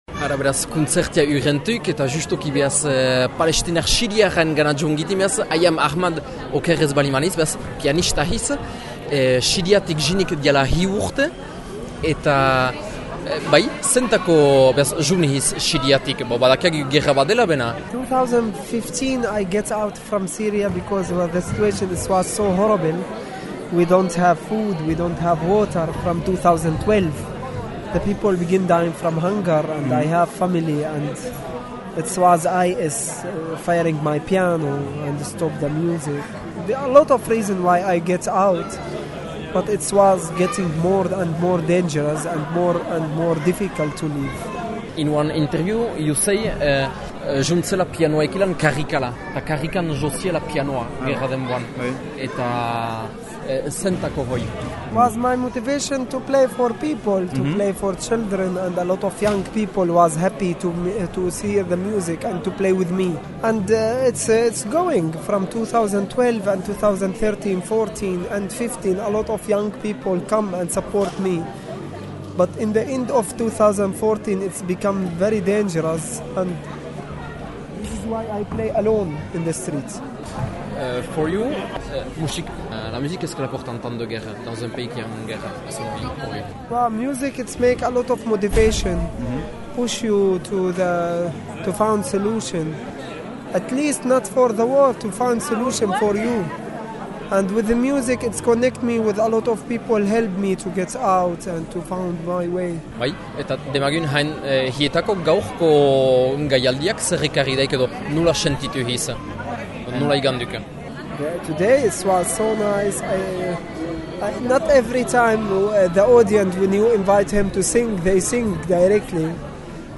Baita, gure ingles traketsarekin, Aeham Ahmad Siriar errefusiatu, piano'joilearengana ere:
aeham ahmad siriar piano joilea.mp3